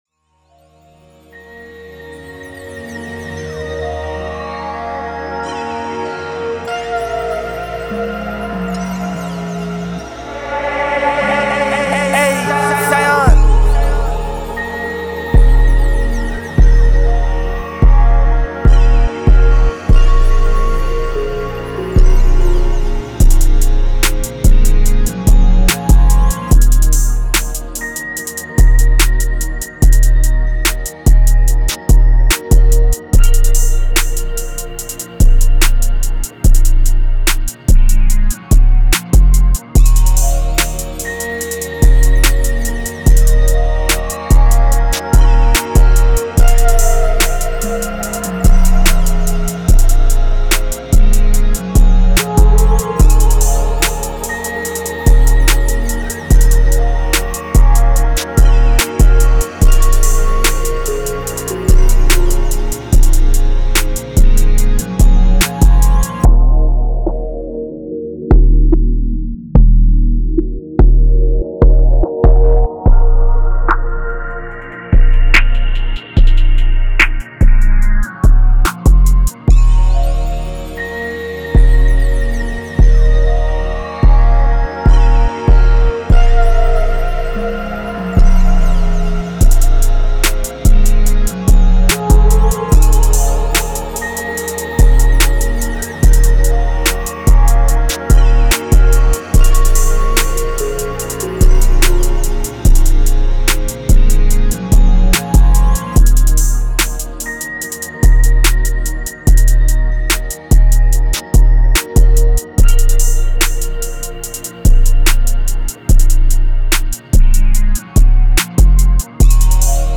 145 D# Minor